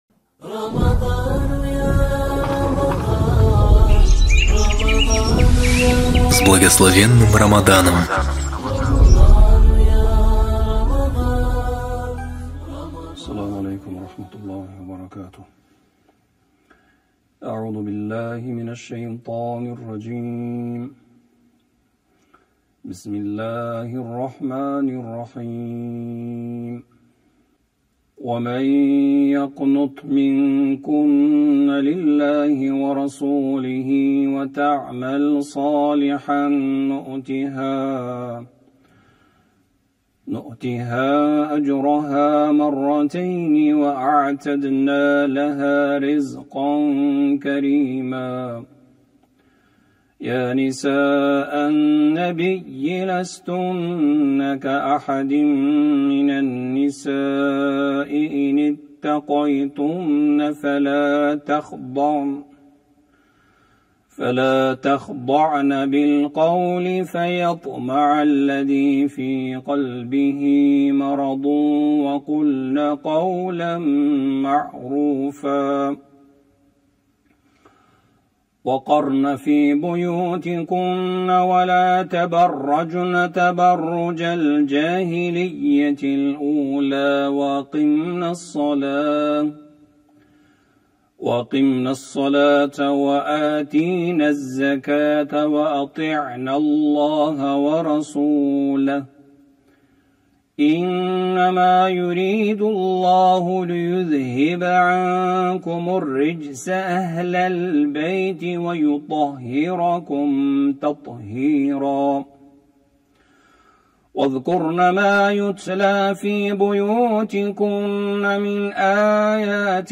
Хатм Корана читаемый в Центральной мечети г.Алматы в период Священного месяца Рамадан.